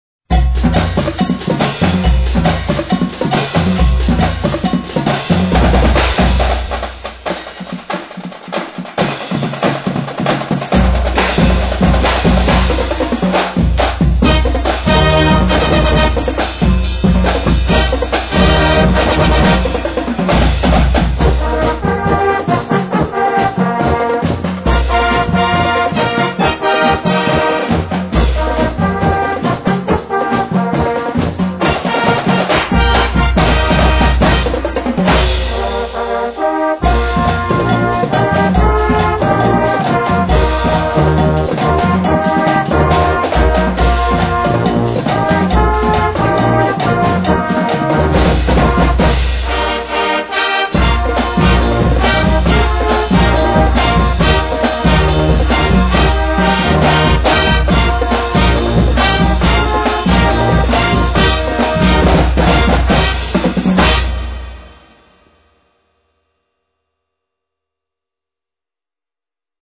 Besetzung: Naturtonfarenzug.
Musik für Naturton-Fanfarenzüge